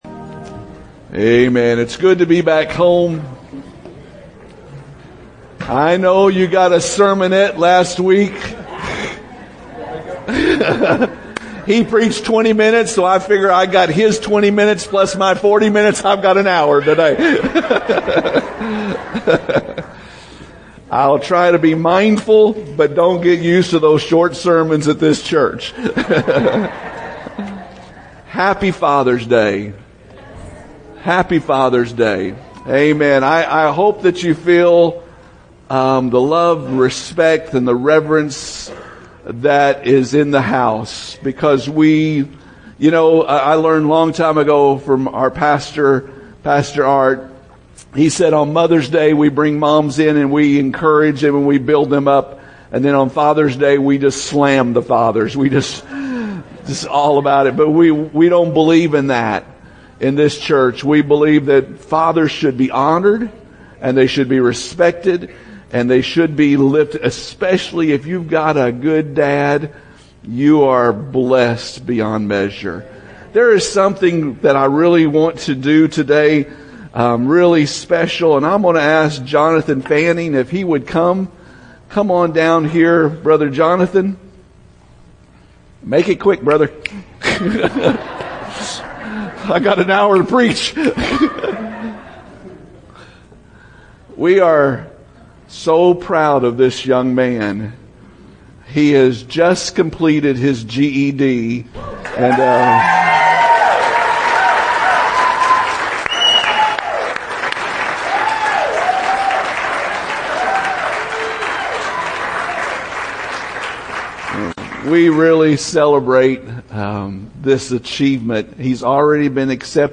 Podcast: Father’s Day Sermon
fathersdaysermon.mp3